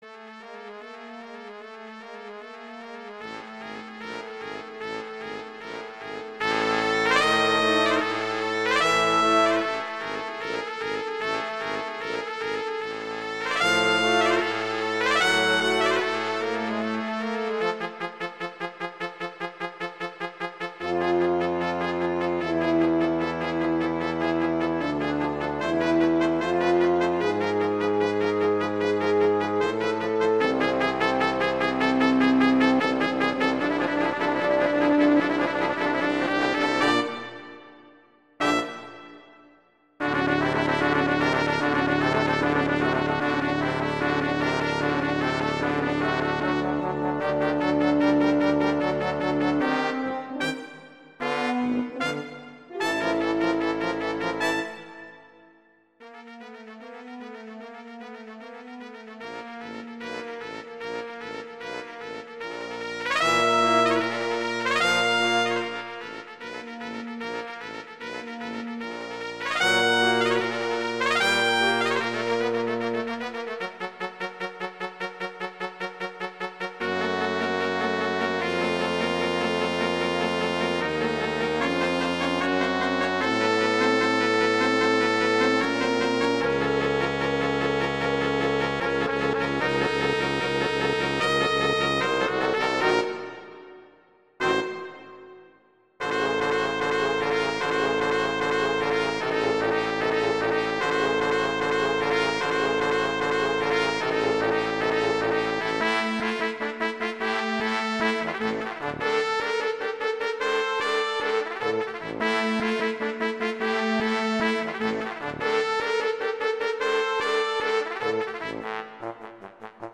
classical, halloween, holiday
D minor
♩=150 BPM (real metronome 152 BPM)